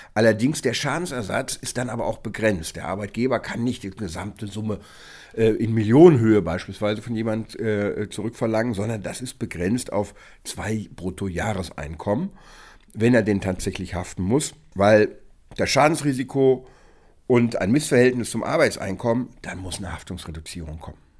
O-Ton: Arbeitnehmer haftet nicht in voller Höhe – Arbeitsgericht begrenzt Ersatzpflicht – Vorabs Medienproduktion